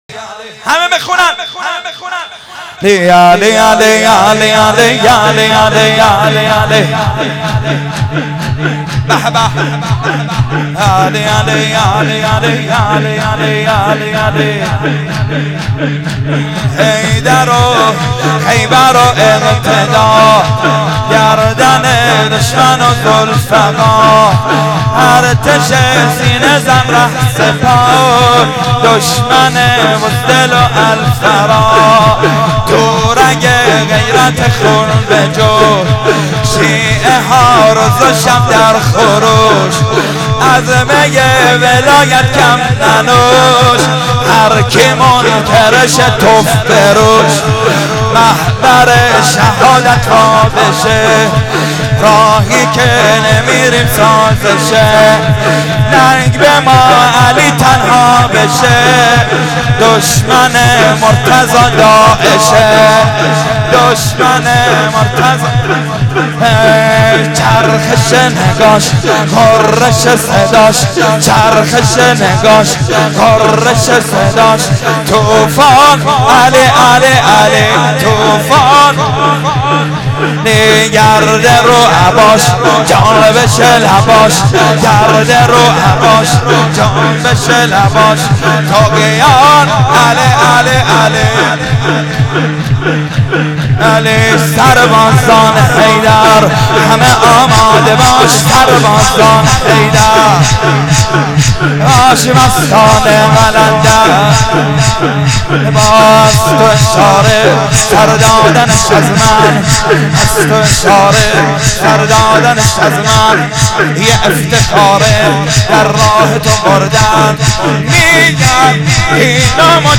مدح امیرالمومنین: